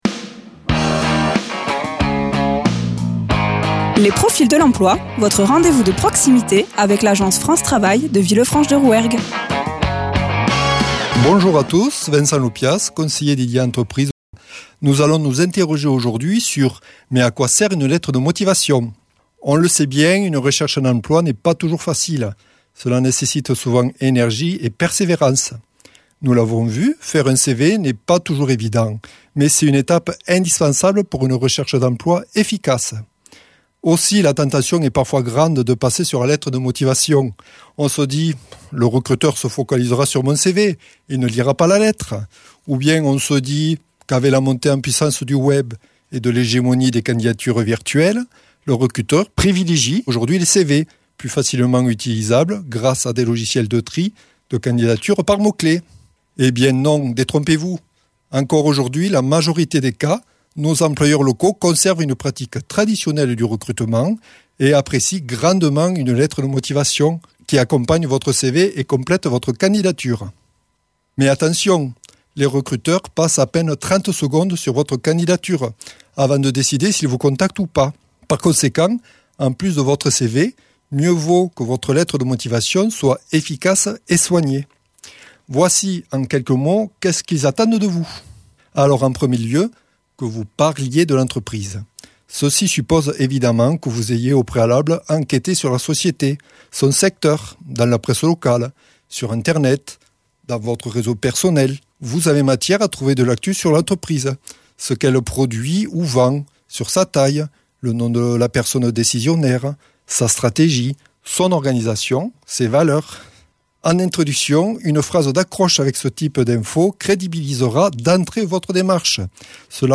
Interviews
Présenté par Les conseillers de France Travail,